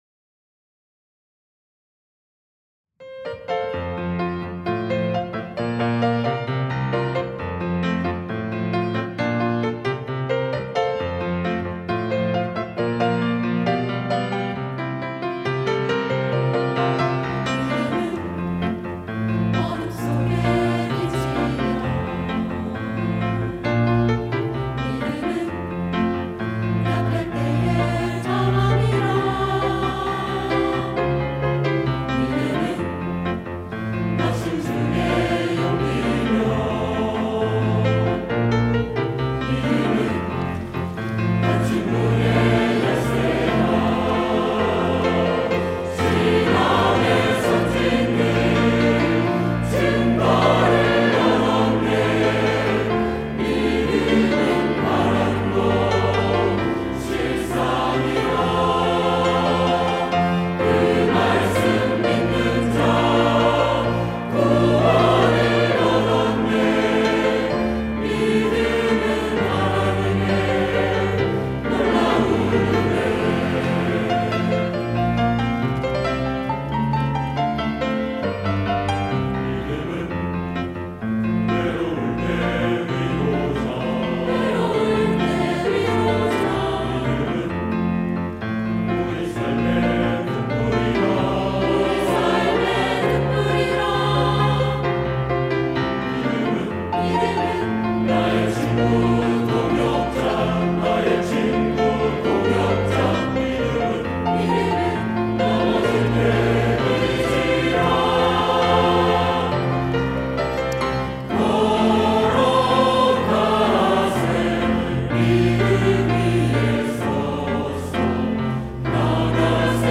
할렐루야(주일2부) - 믿음은
찬양대